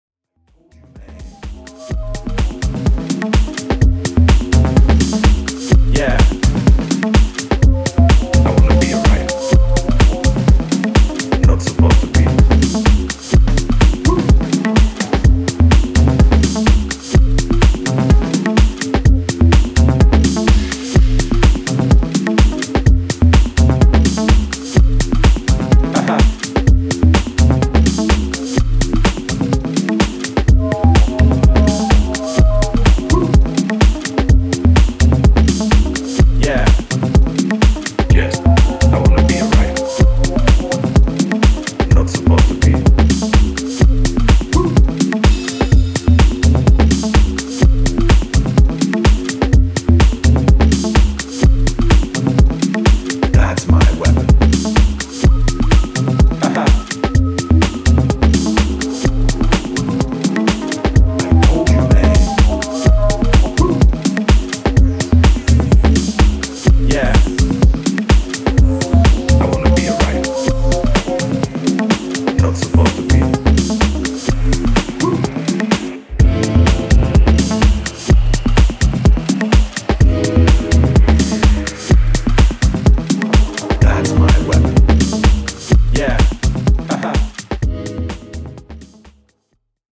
軟性のグルーヴで足取りを奪う